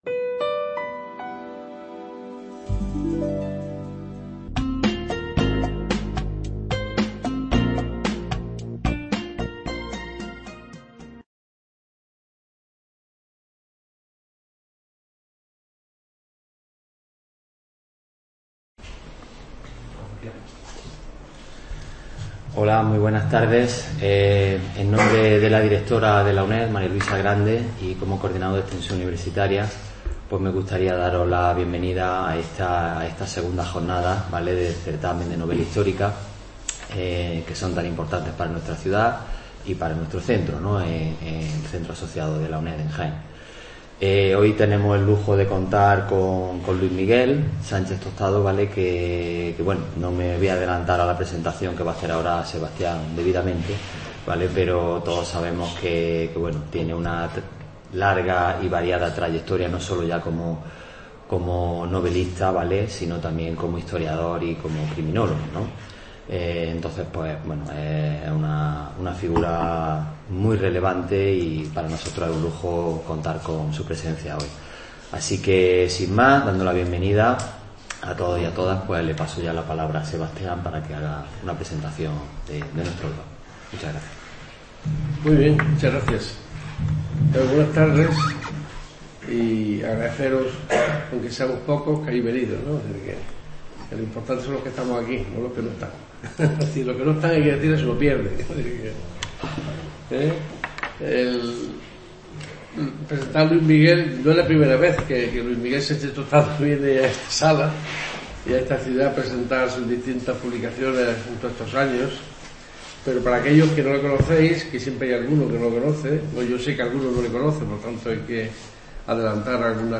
mantiene un encuentro literario con sus lectores en el Centro Asociado de la UNED en Úbeda